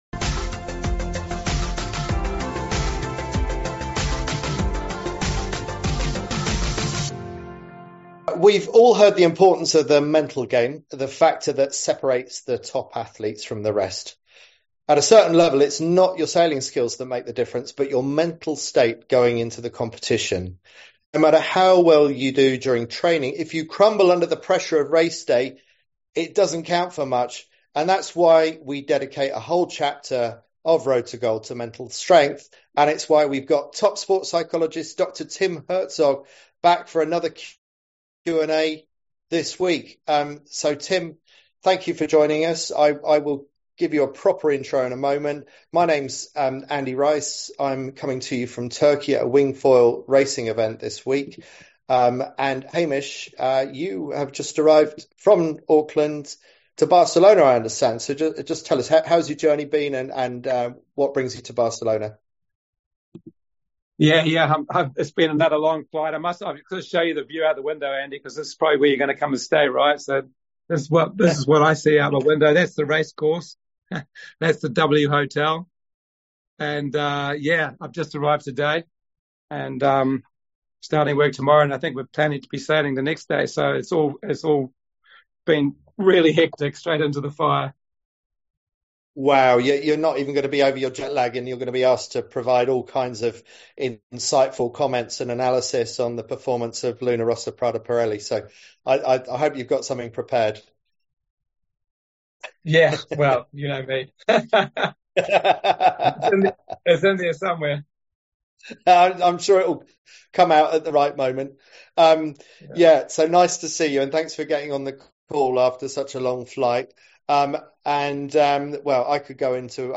Our weekly Q&A sessions on Zoom, to answer your burning questions and enlighten you on your Road To Gold